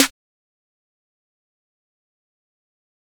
Snare 2.wav